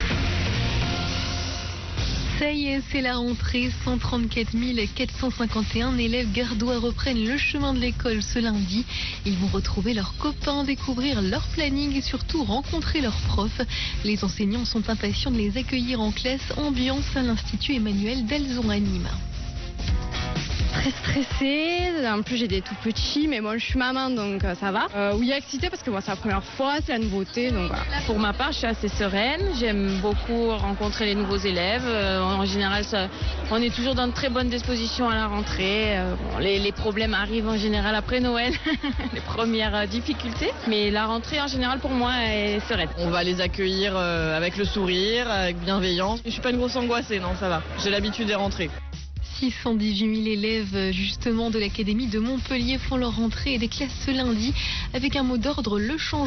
Interview NRJ